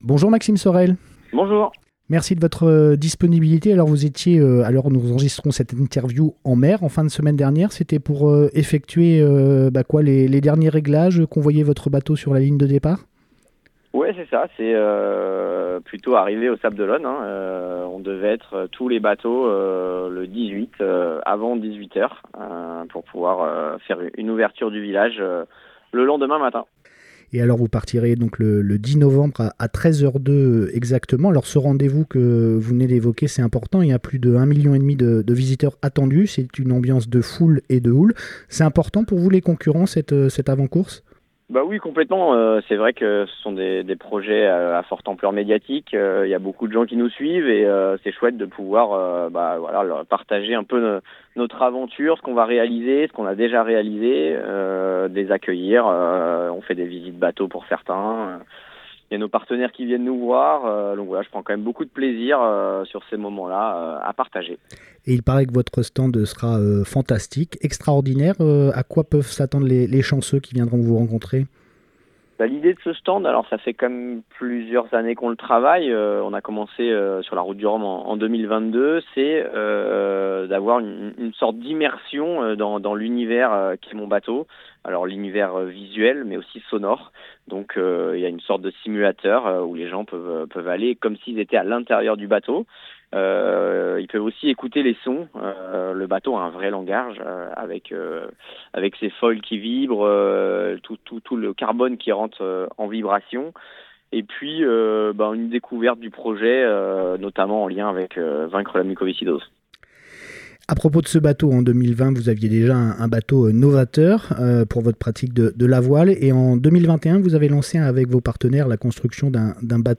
Entretien avec le skipper hors norme.